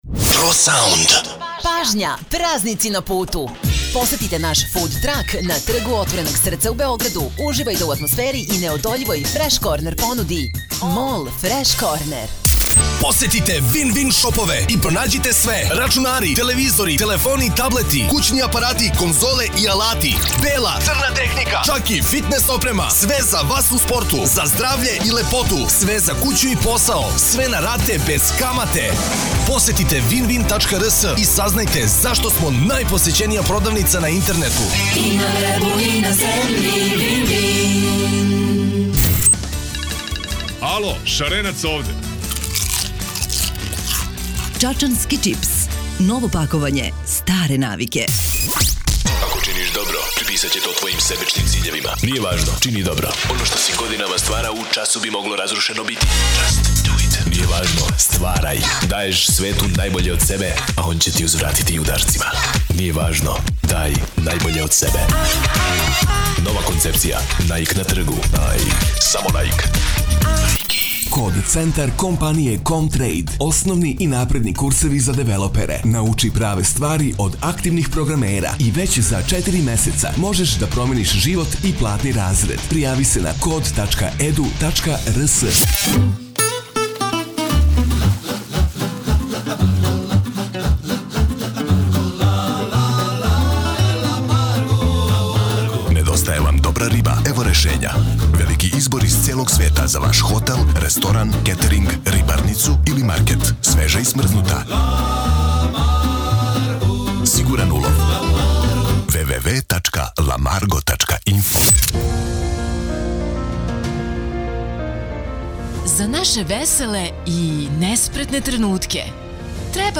RADIO REKLAME